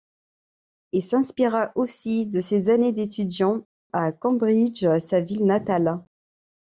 Pronounced as (IPA) /kɑ̃.bʁidʒ/